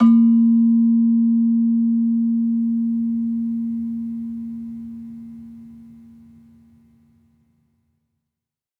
Gender-3-A#2-f.wav